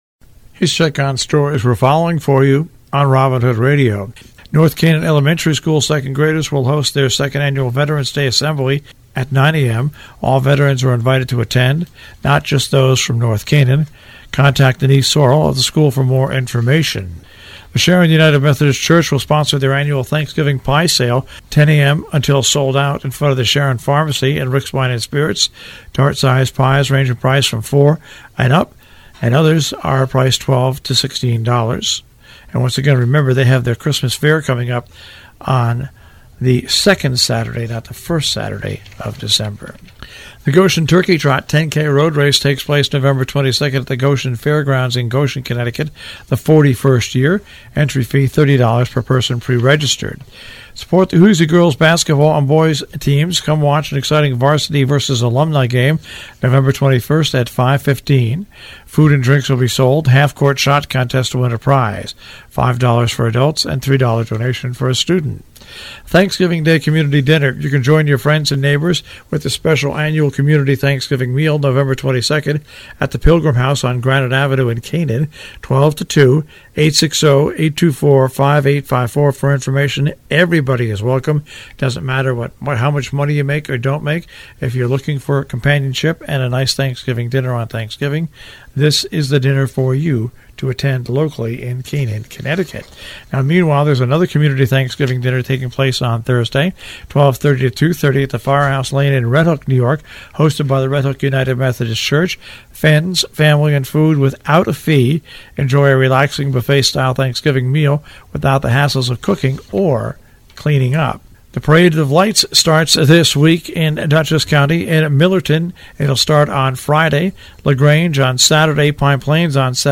covers news and events in the Tri-State Region on The Breakfast Club on Robin Hood Radio